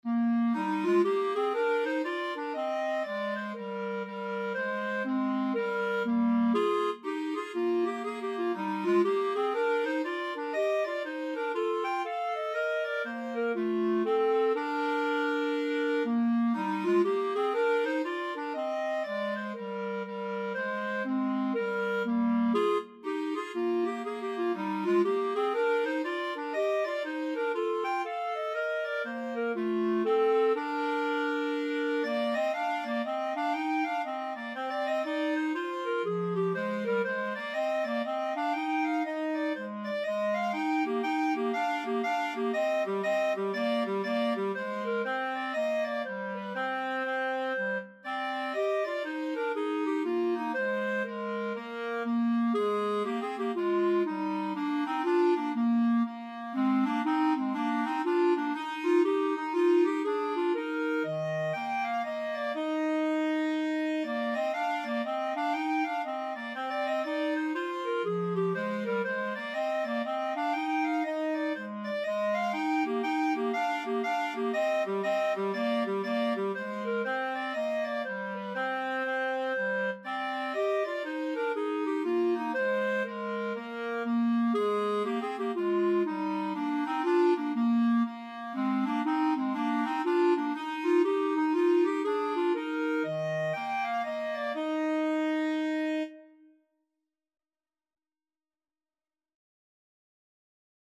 Clarinet 1Clarinet 2
2/2 (View more 2/2 Music)
Clarinet Duet  (View more Intermediate Clarinet Duet Music)
Traditional (View more Traditional Clarinet Duet Music)